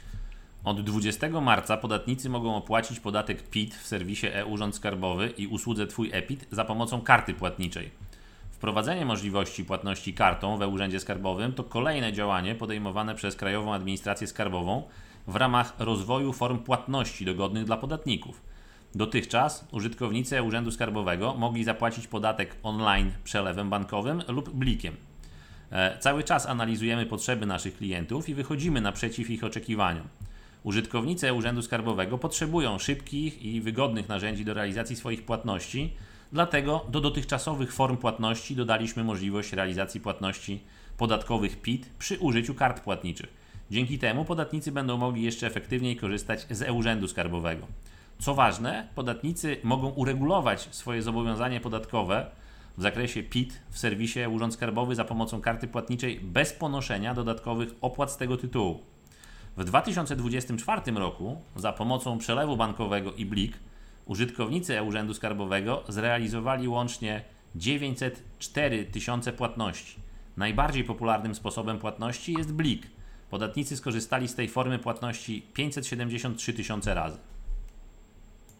Płatność kartami w e-US - posłuchaj wypowiedzi rzecznika prasowego